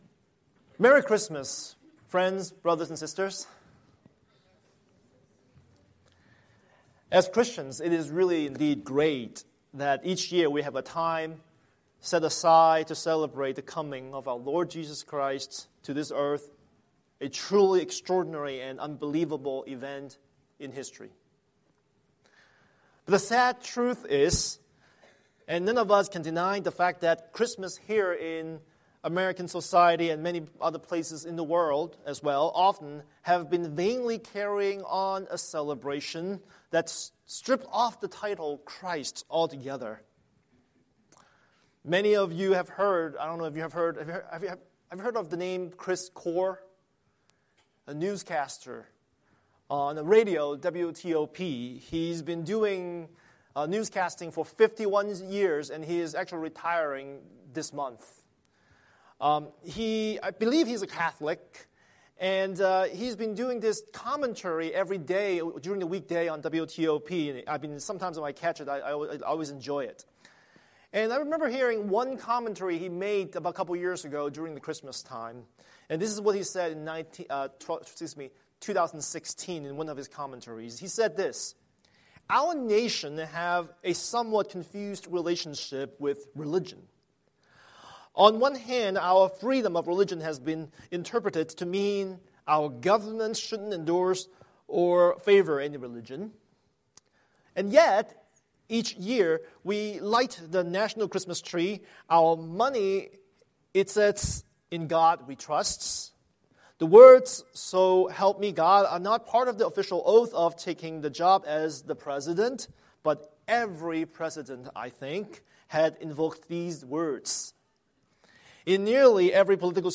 Scripture: Matthew 1:18-25 Series: Sunday Sermon